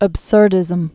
(əb-sûrdĭz-əm, -zûr-)